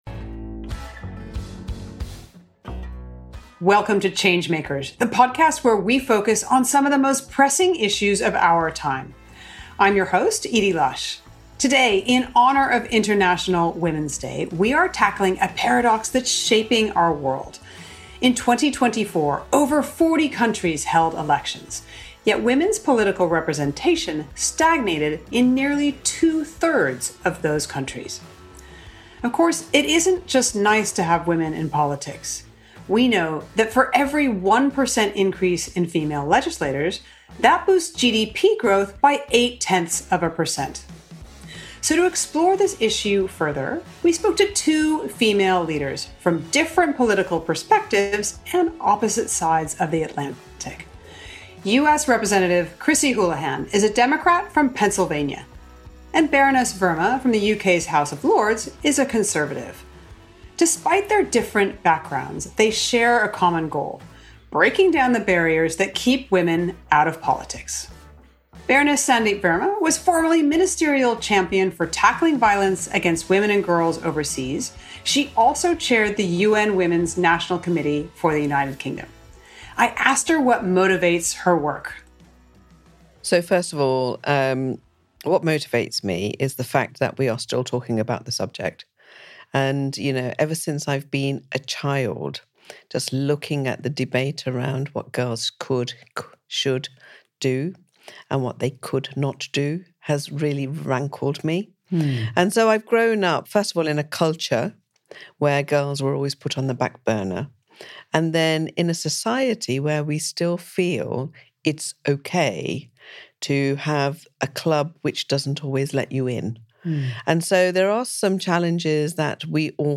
It’s a conversation about leadership, resilience, and why progress for women is progress for everyone.